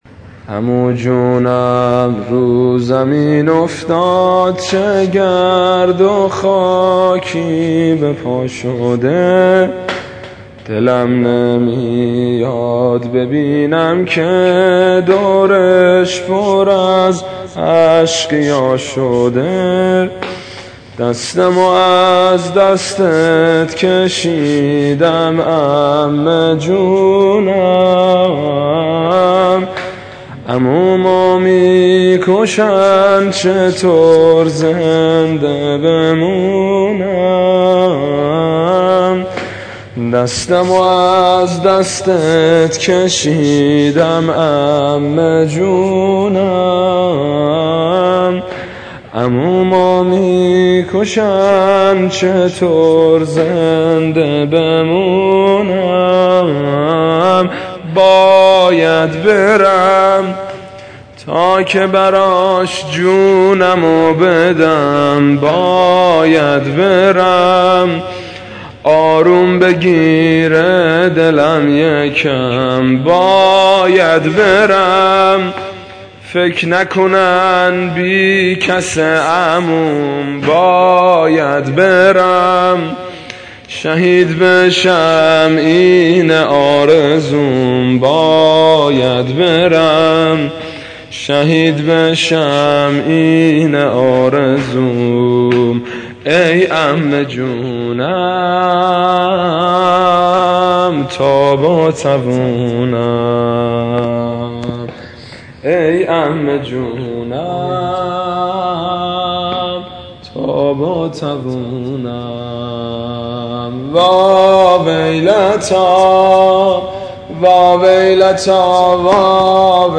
واحد شب عاشورا -( میذاری دستاتو توو دستم / میگیره آروم دلم از غم )